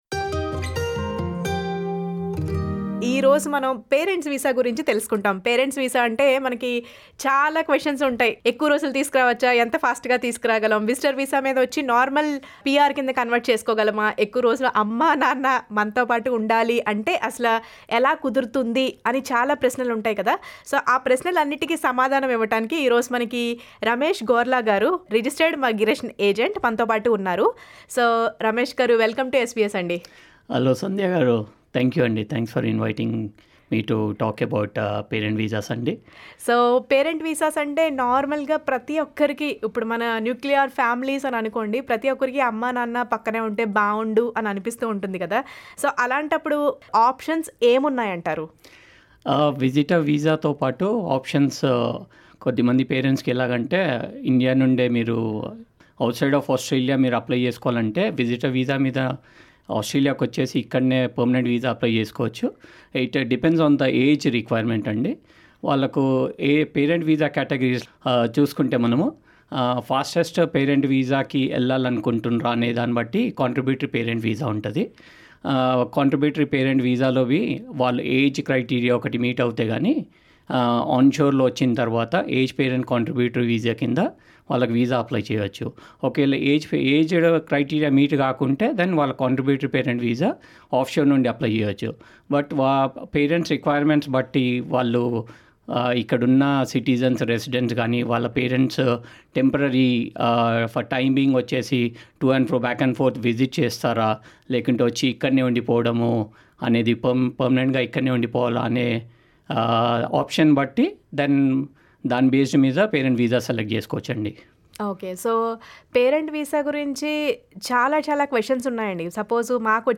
a registered migration agent